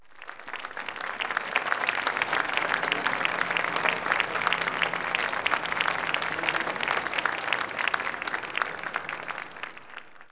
clap_027.wav